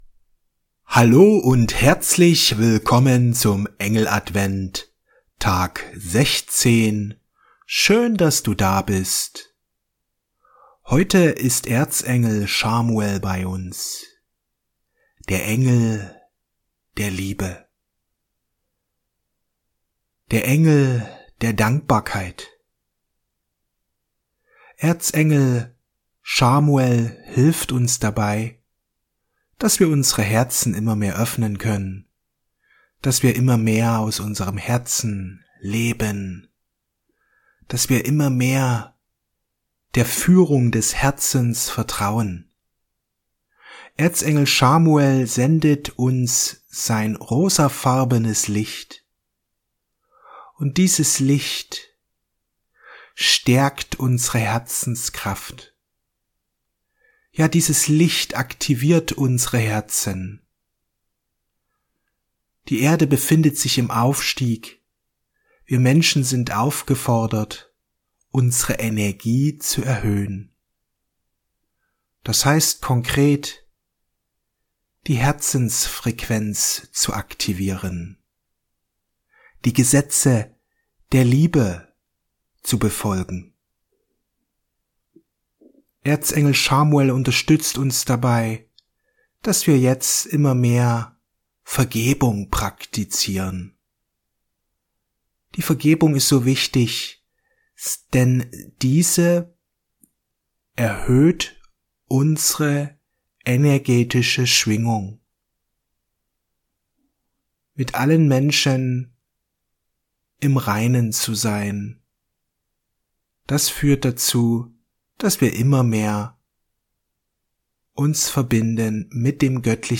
Die Kraft der Vergebung Meditation mit Erzengel Chamuel